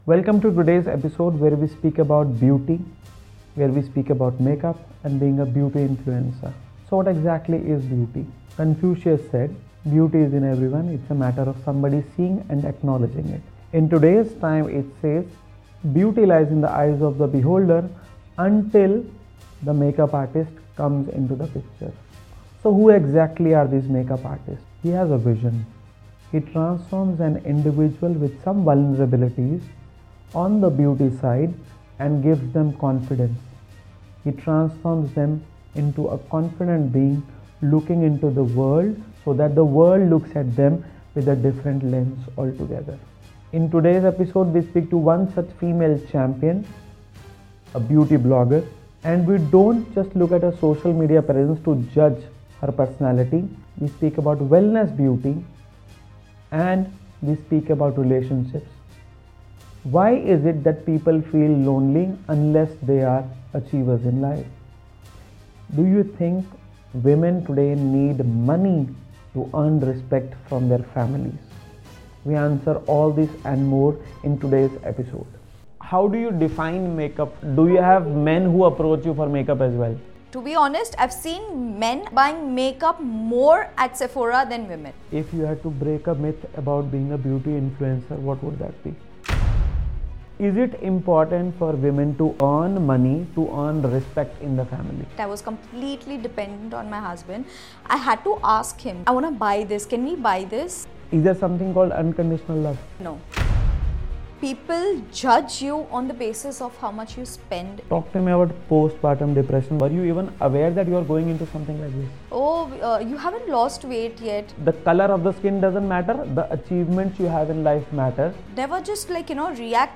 The podcast covers themes such as personal struggles, resilience, and the pursuit of dreams, with episodes featuring candid dialogues about the highs and lows of life.